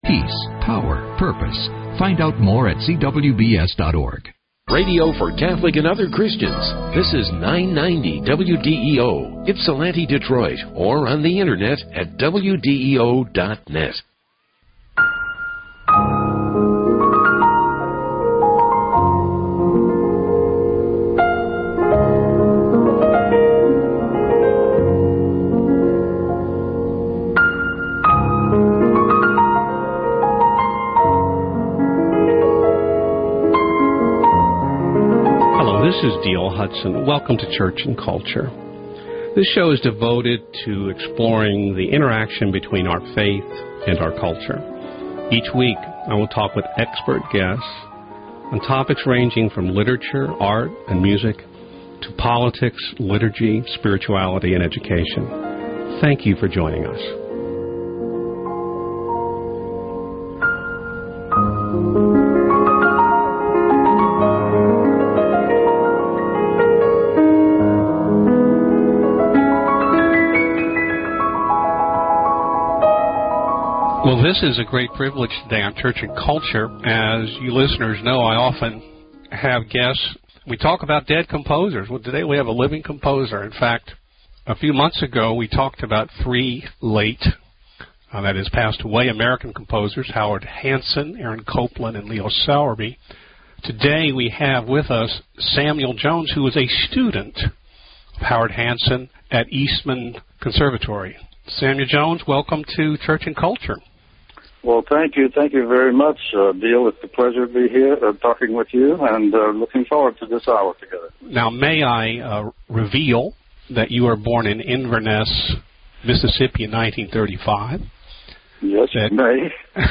Samuel Jones: – Hour 1 – Composer Samuel Jones Plays and Discusses Early and Late Works, March 25, 2017